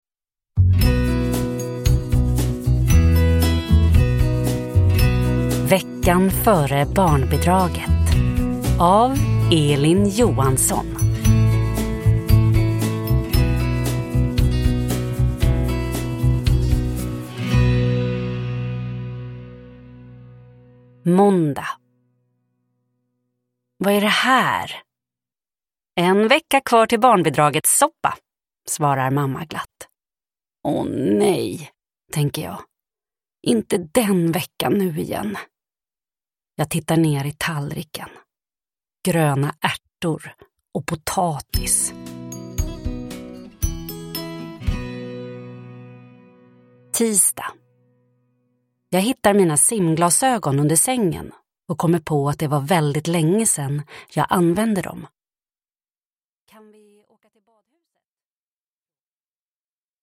Veckan före barnbidraget – Ljudbok – Laddas ner